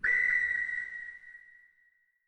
ENEMY_CHARIOT_DEATH.ogg